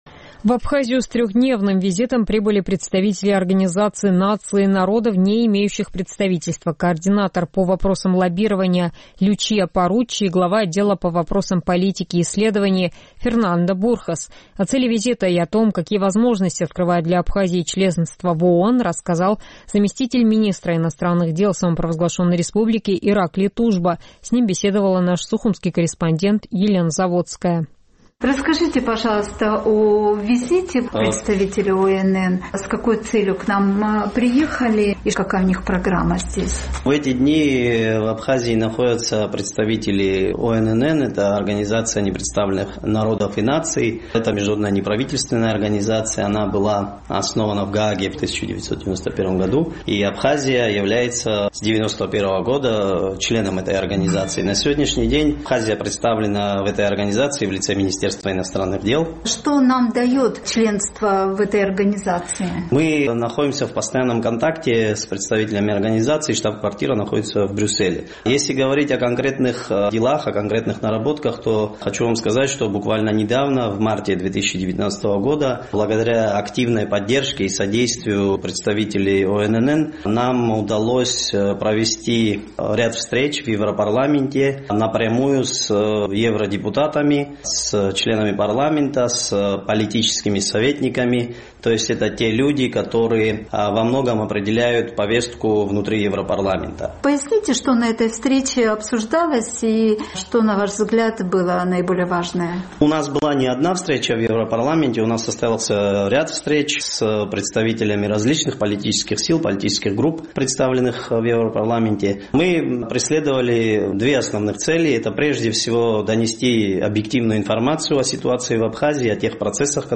В Абхазию с трехдневным визитом прибыли представители Организации непризнанных народов и наций. О том, какие возможности открывает для Абхазии членство в ОНН, рассказал замминистра иностранных дел Ираклий Тужба.